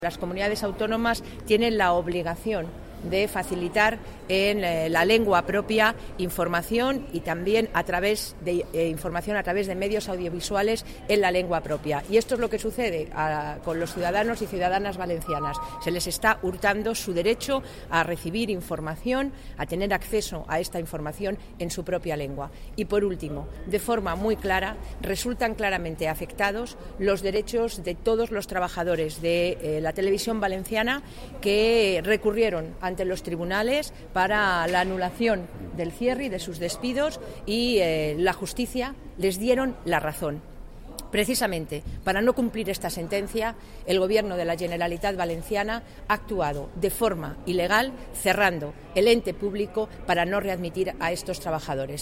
Declaraciones de Soraya Rodríguez en el Tribunal Constitucional tras presentar recurso de inconstitucionalidad contra el cierre de la Radio y TV Valenciana 24/02/2014